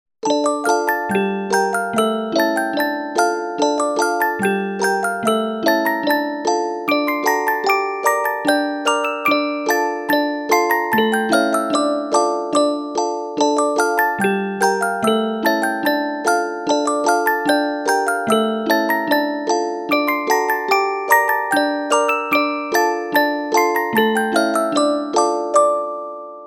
Ретро рингтоны